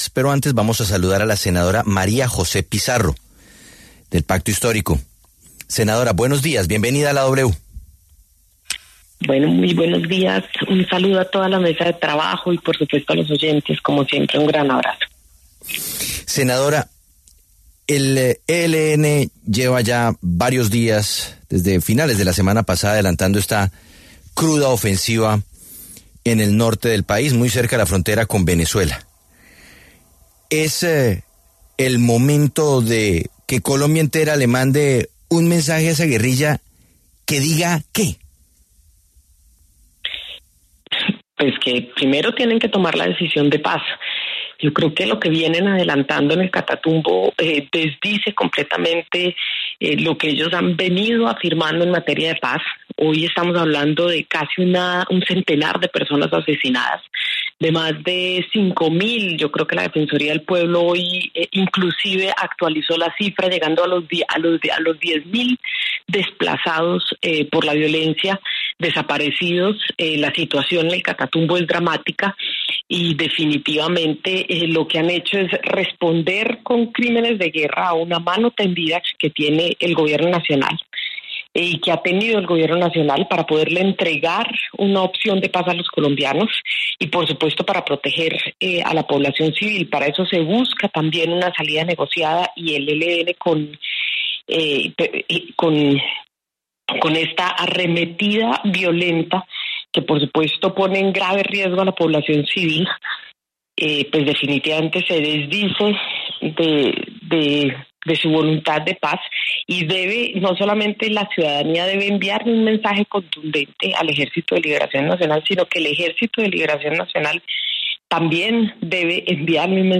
En medio de la crisis humanitaria que vive el Catatumbo, la senadora María José Pizarro, del Pacto Histórico, pasó por los micrófonos de La W e hizo un llamado al Ejército de Liberación Nacional (ELN) para que cese de inmediato la ofensiva y demuestre un verdadero compromiso con la paz.